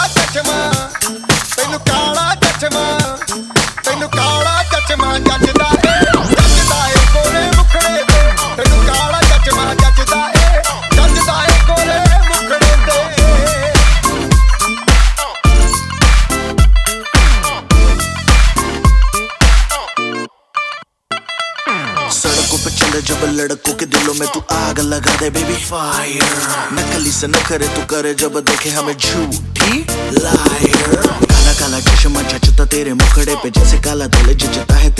# Bollywood